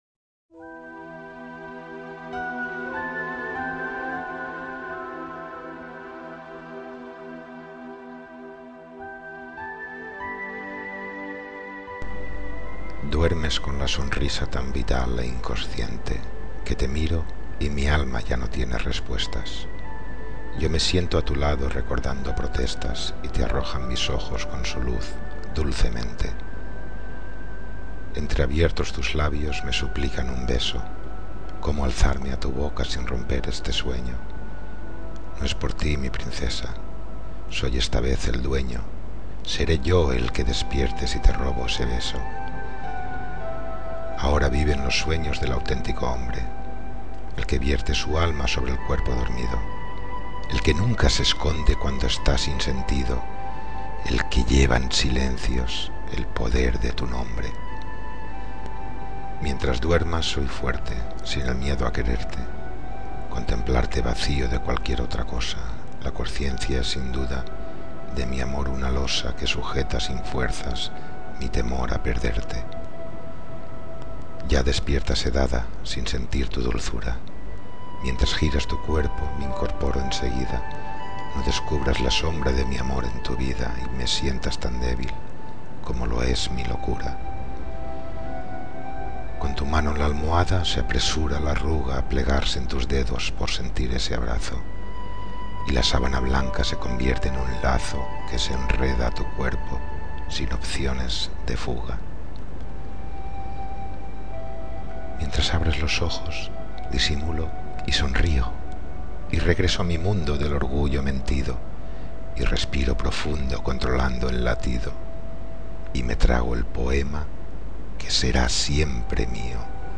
Inicio Multimedia Audiopoemas Duermes.